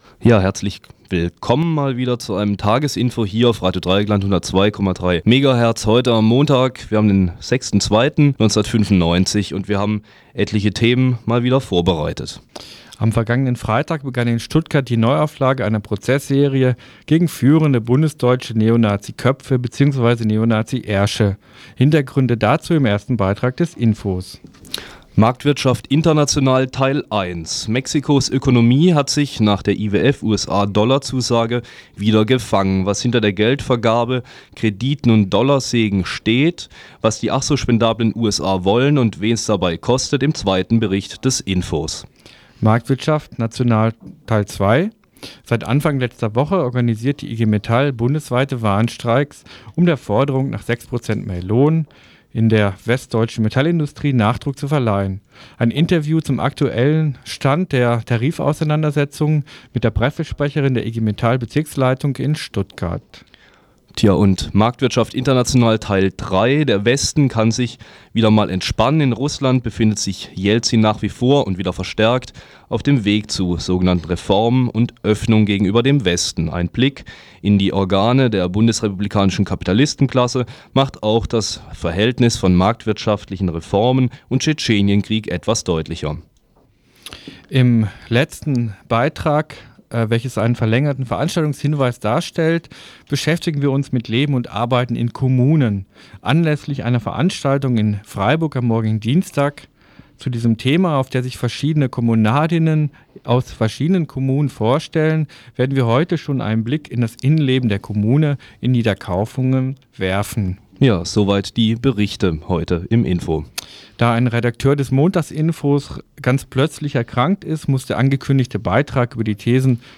Bericht über Neonaziprozeß/ANS/NA-Kader in Stuttgart. Interview